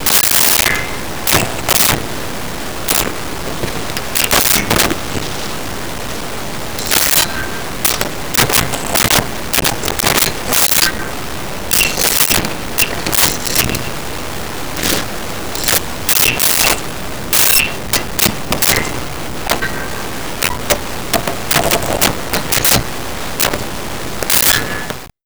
Glass Clinks And Motion 02
Glass Clinks And Motion 02.wav